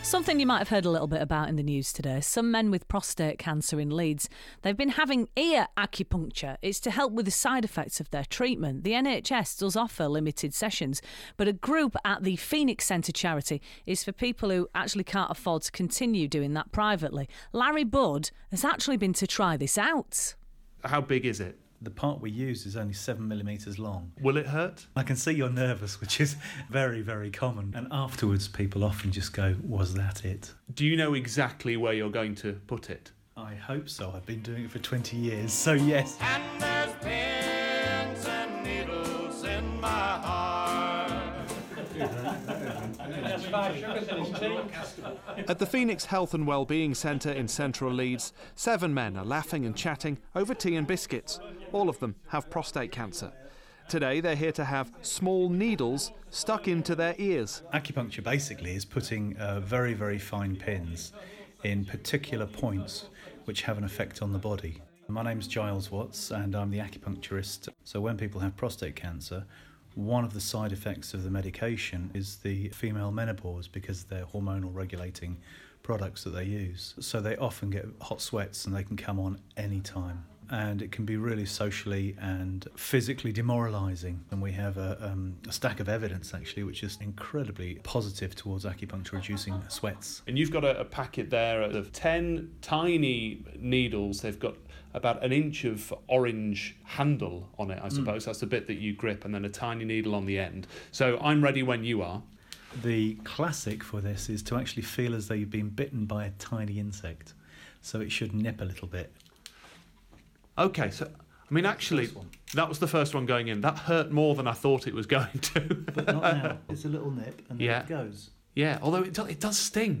Checkout this BBC Radio recent interview with some of our members who are using acupuncture for relief of the side effects of hormone treatment, which take place at the Phoenix Health and Wellbeing Centre in Leeds.